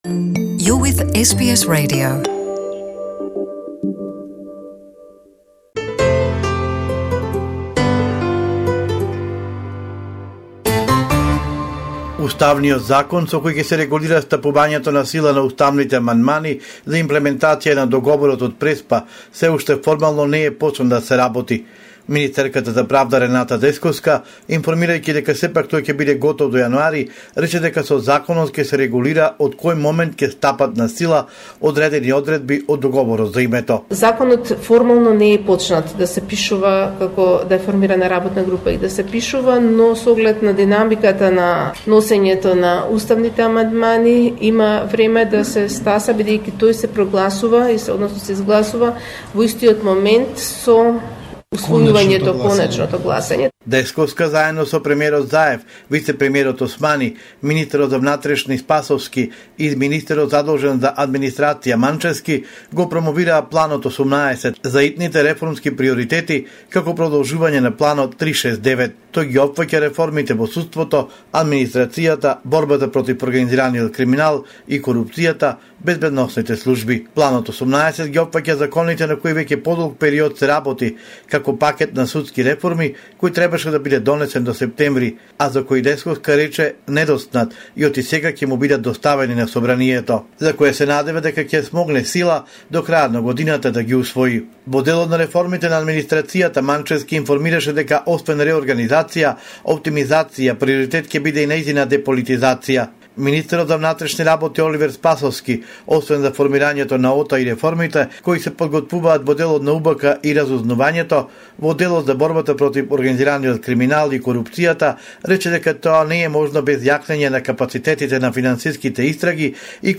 He anticipates a heated parliamentary debate. Parliament Speaker Talat Xhaferi says even if VMRO-DPMNE wanted to, it cannot prevent changes to the Constitution through filibustering. Report